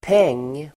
Uttal: [peng:]